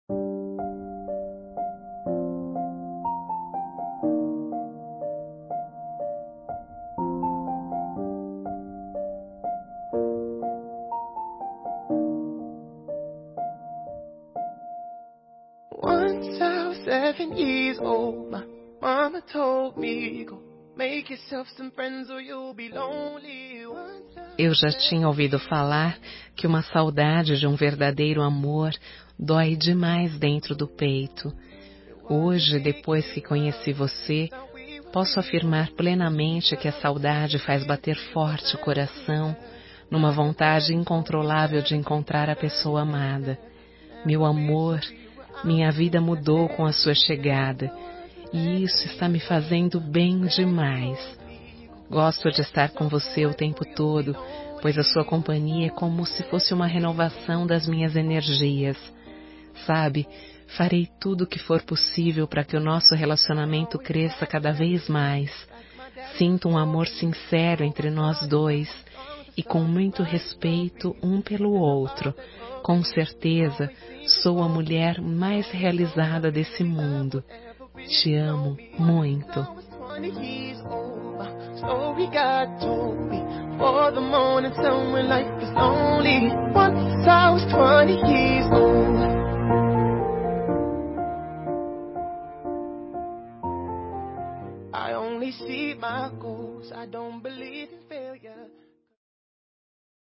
Saudade Voz Feminina